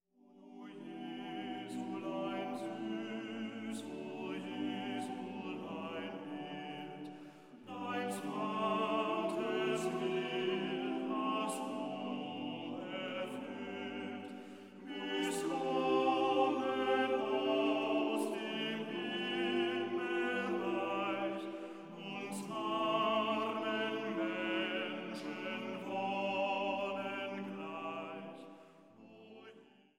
Weihnachtliche Chorsätze und Orgelmusik